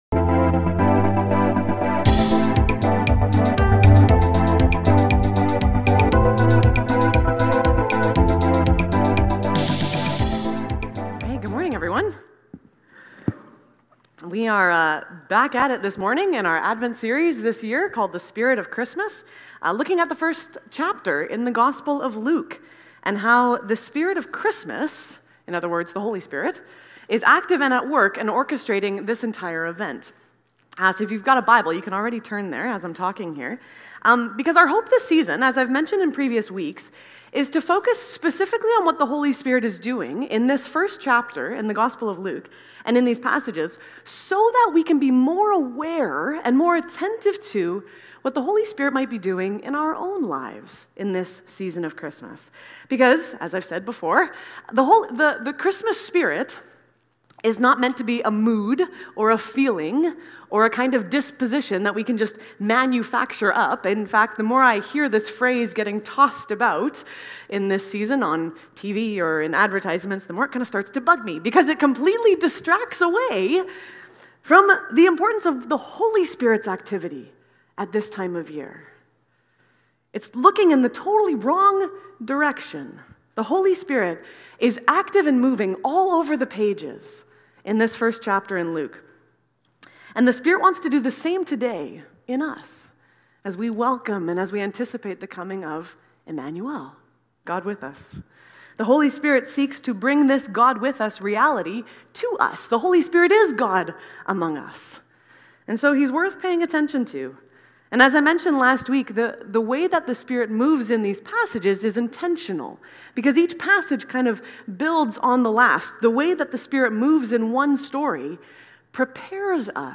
Willoughby Church Sermons | Willoughby Christian Reformed Church